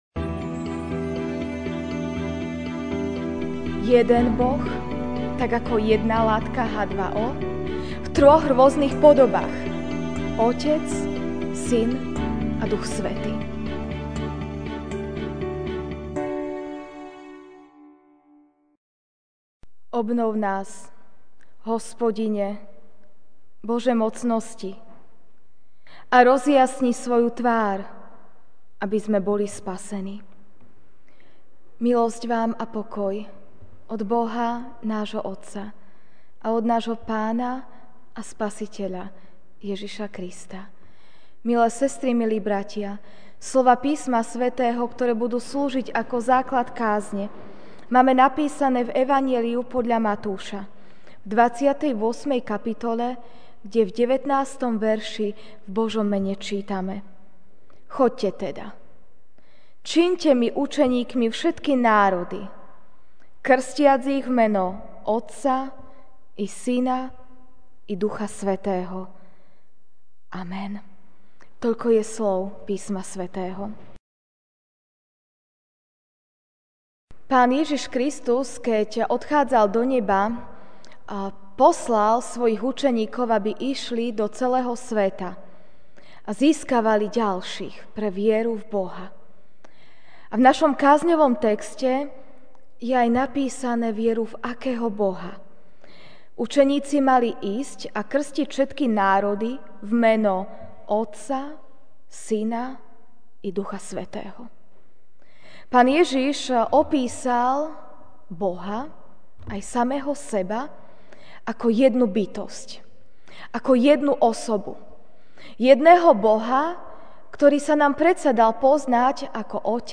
jún 11, 2017 1 v 3 MP3 SUBSCRIBE on iTunes(Podcast) Notes Sermons in this Series Večerná kázeň: 1 v 3 (Mt. 28, 19) Choďte teda, čiňte mi učeníkmi všetky národy, krstiac ich v meno Otca i Syna i Ducha Svätého ...